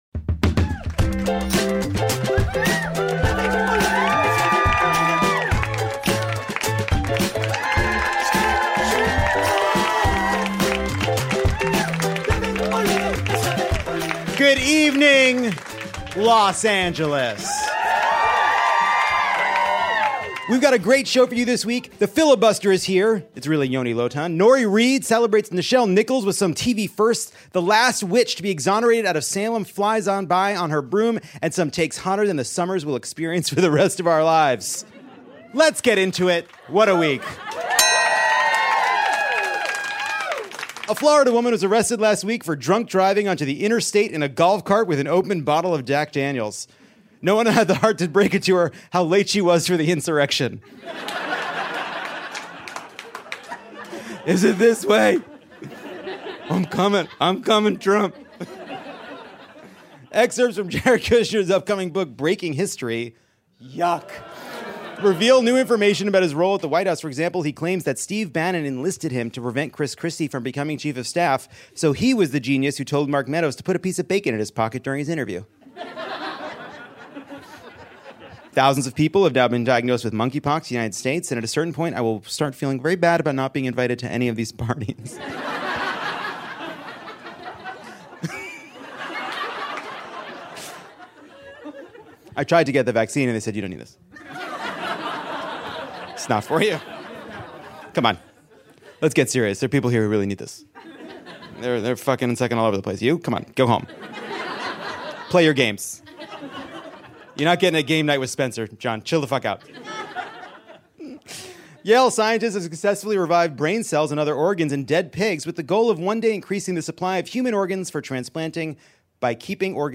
It’s the dog days of summer and Lovett or Leave It is unleashed on L.A.’s Dynasty Typewriter theater.